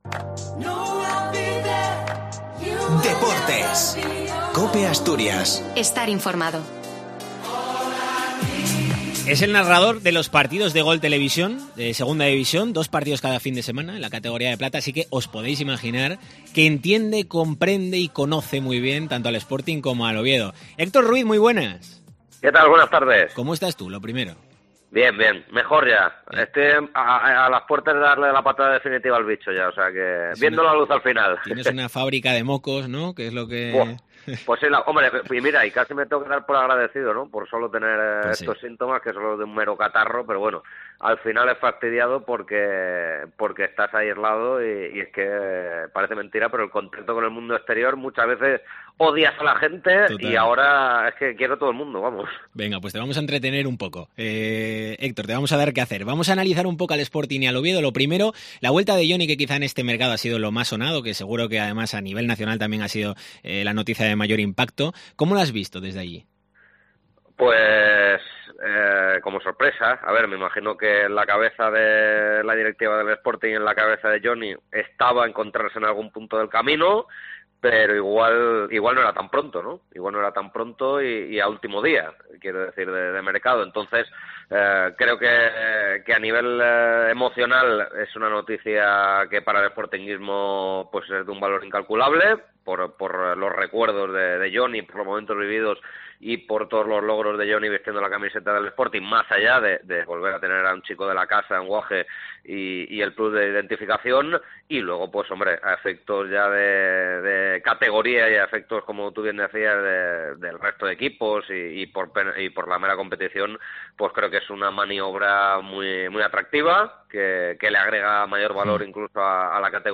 ANÁLISIS SPORTING-OVIEDO